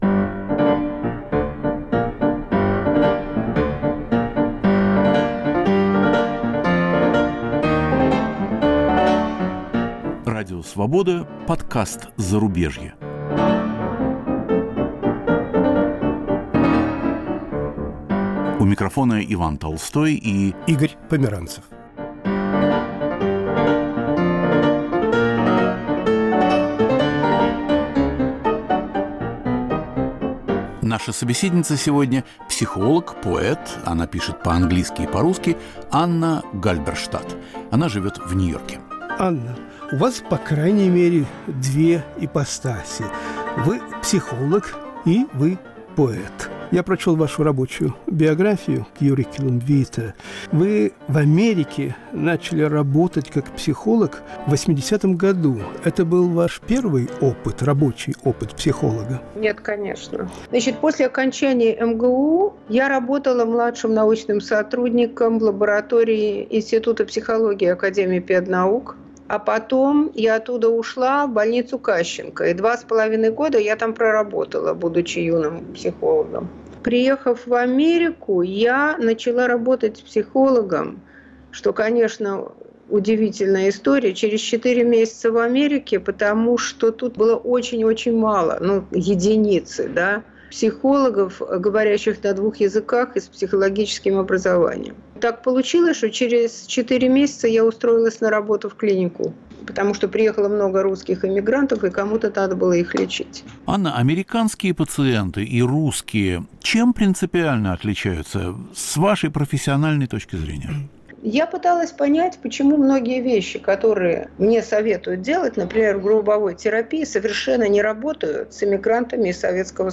Наша собеседница